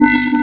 se_timeout2.wav